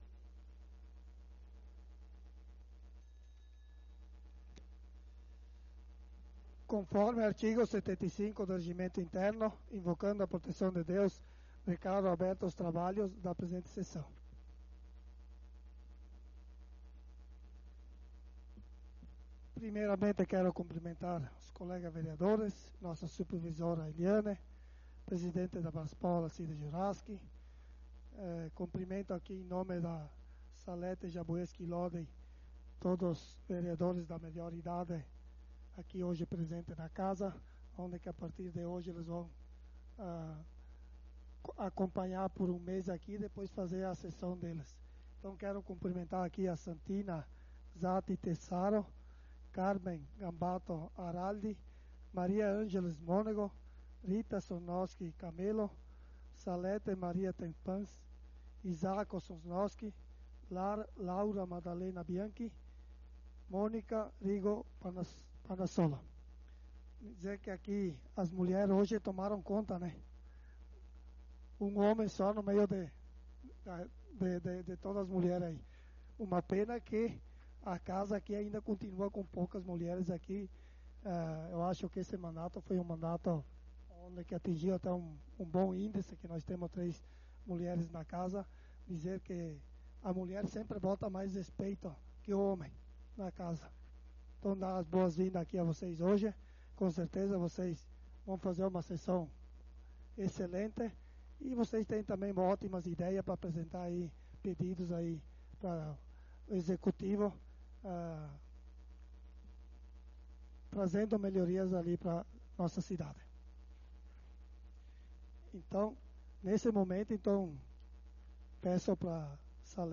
Sessão Ordinária do dia 05/11/2025